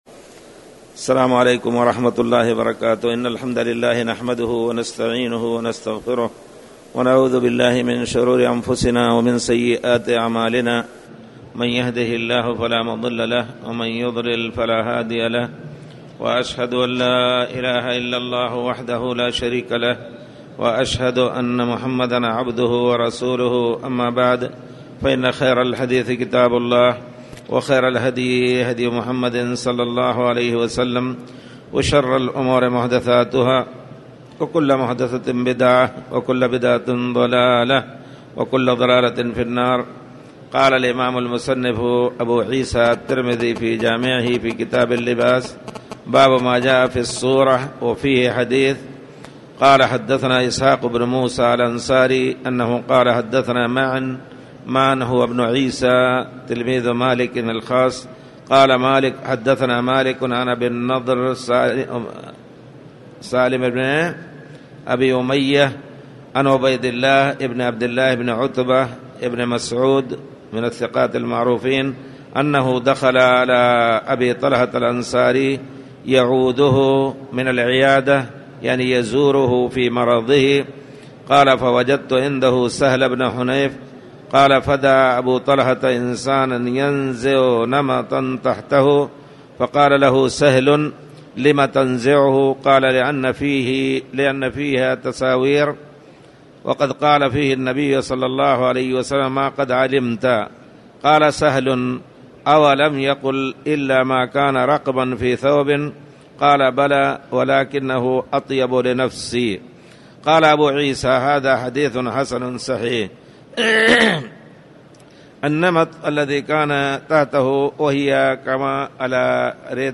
تاريخ النشر ١ رمضان ١٤٣٨ هـ المكان: المسجد الحرام الشيخ